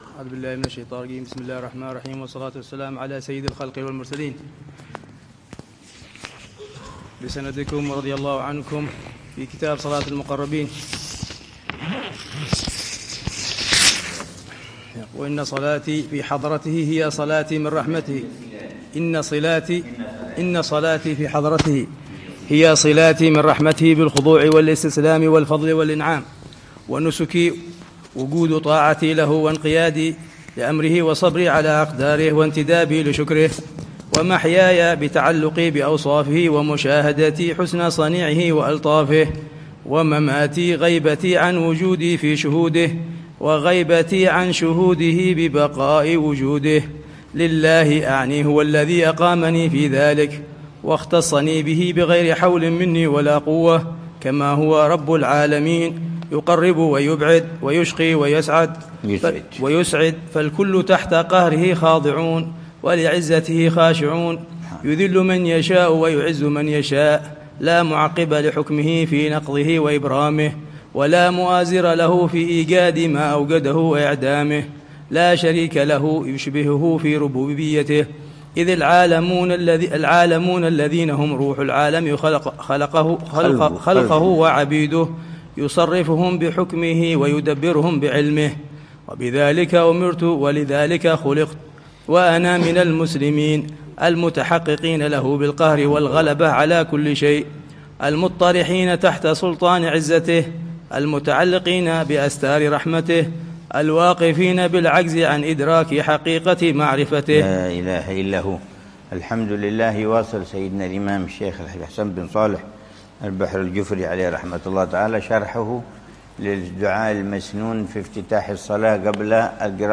الدرس الثاني من شرح العلامة الحبيب عمر بن حفيظ لكتاب صفة صلاة المقربين للعلامة الحبيب الحسن بن صالح البحر الجفري رحمه الله، يوضح فيها صفة صلاة